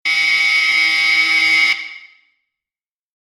Basketball-buzzer-2.mp3